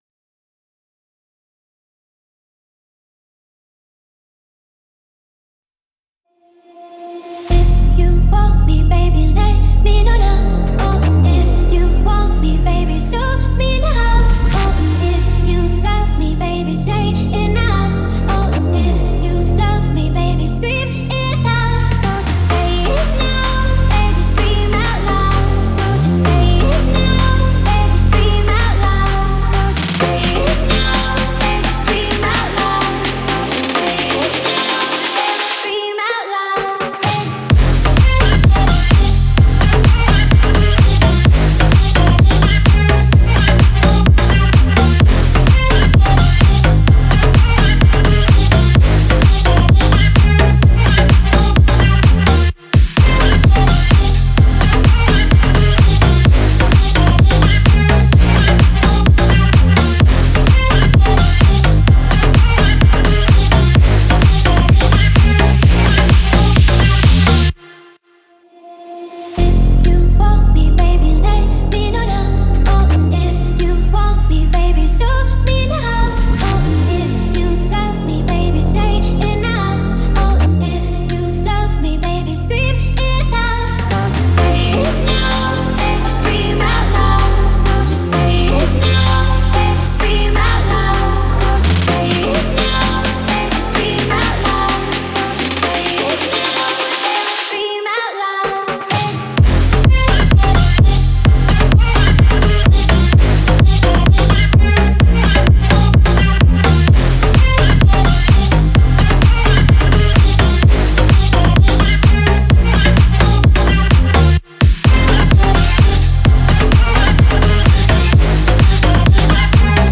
это трек в жанре поп с элементами инди